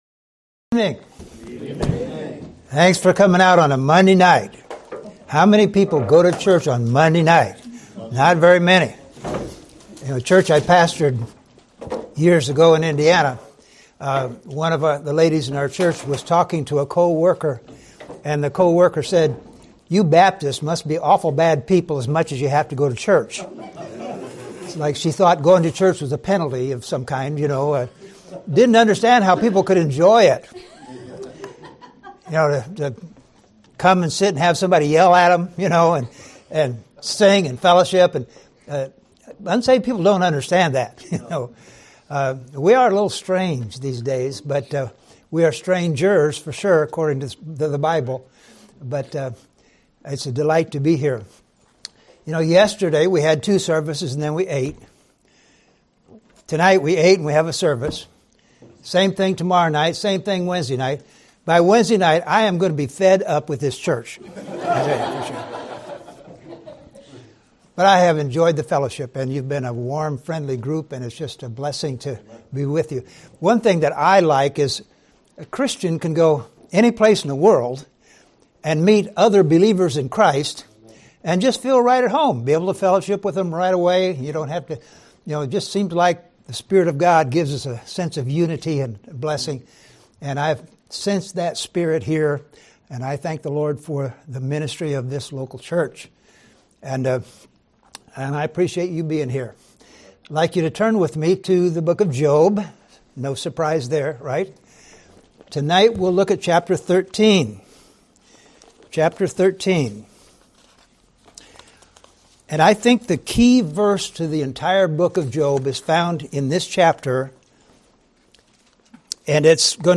Sermons
Show Details → Sermon Information Title Unshakeable Faith Description Message #3 of the 2025 Bible Conference. In this message we learn that it is possible to have unshakeable faith in the midst of trials and difficulties. Unshakeable faith is faith that is not moved by false teachers, drastic circumstances, or hypocrisy in others.